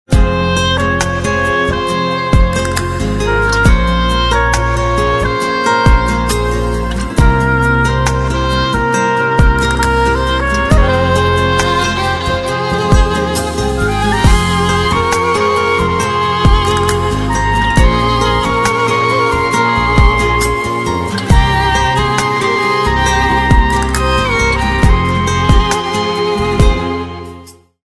زنگ موبایل
رینگتون نرم و بیکلام